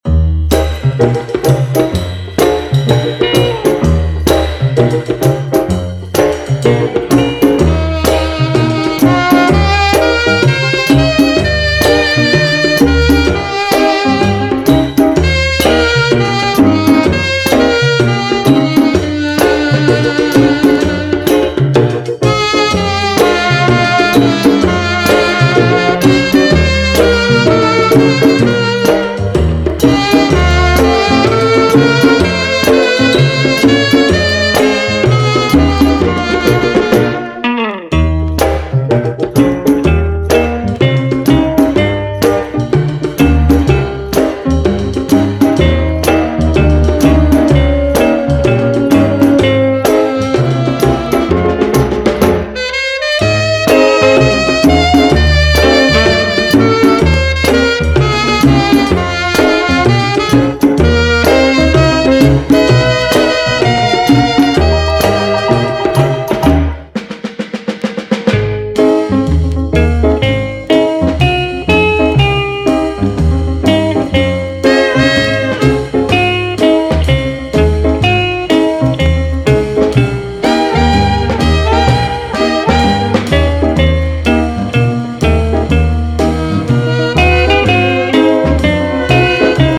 WORLD / BRAZIL / SAMBA (BRA)
レアなサンバ/ラテン・オムニバス！
鮮やかで臨場感あふれる録音が魅力！ブラジルのカーニバルの熱気をそのまま閉じ込めたような多幸感いっぱいの一枚です。